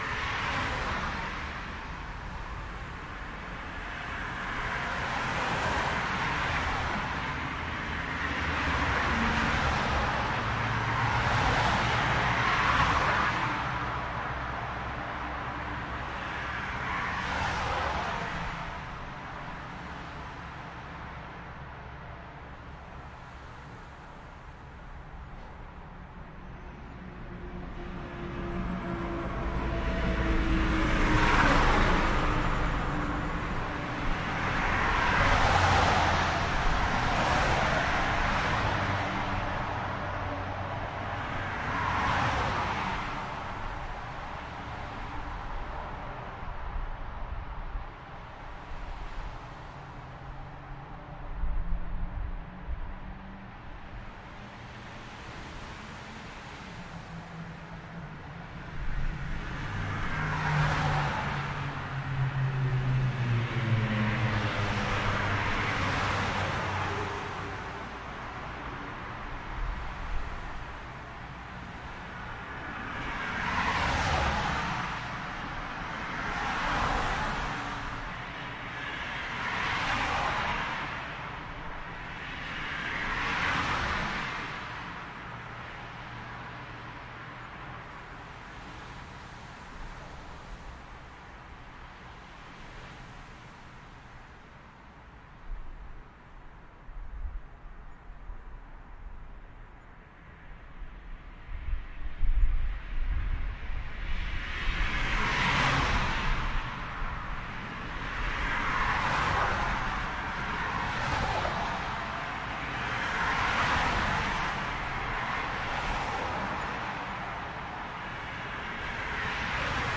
0010 汽车驶过 RL LR
描述：两辆车在高速公路上开车，第一辆车从右到左，第二辆车从左到右。
Tag: 高速公路 现场记录 汽车 driveby